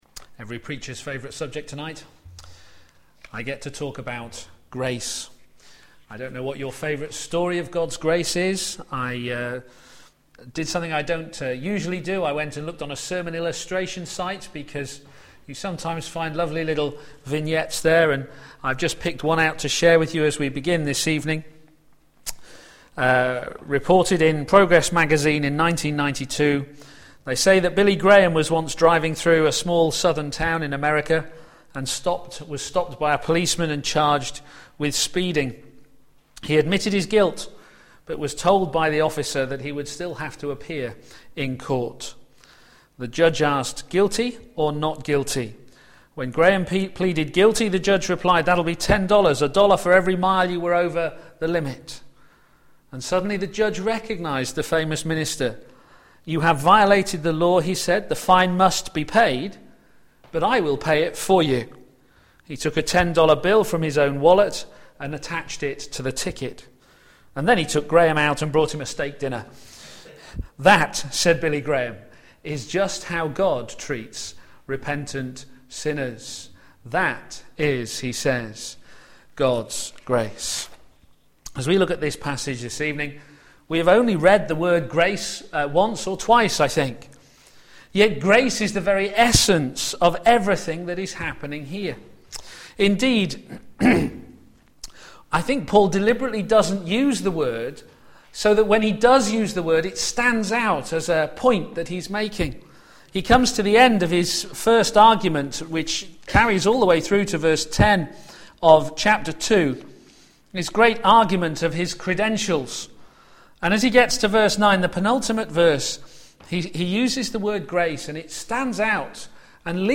p.m. Service
Paul's Apostolic Authority Sermon